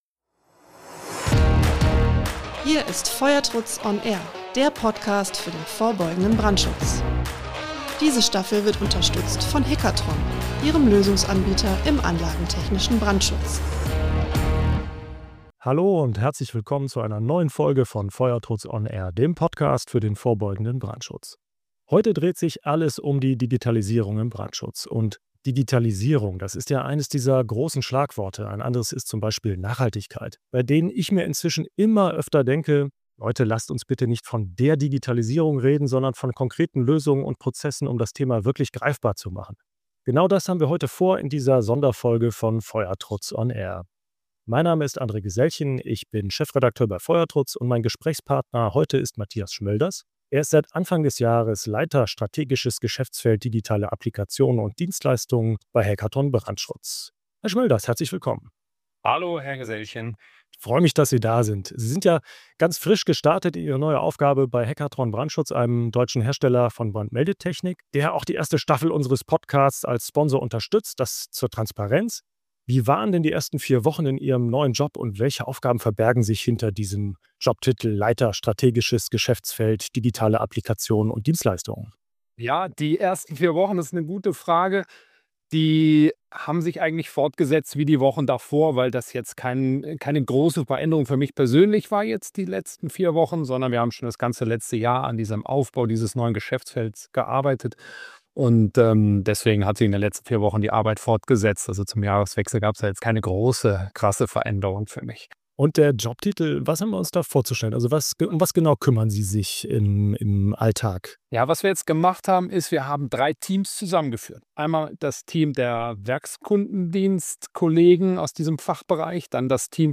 Sonderfolge: Digitalisierung im Brandschutz | Im Gespräch